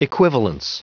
Prononciation du mot equivalence en anglais (fichier audio)
Prononciation du mot : equivalence